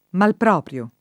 vai all'elenco alfabetico delle voci ingrandisci il carattere 100% rimpicciolisci il carattere stampa invia tramite posta elettronica codividi su Facebook malproprio [ malpr 0 pr L o ] agg.; pl. m. ‑pri — anche mal proprio [id.]